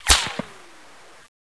weapon_whizz1.wav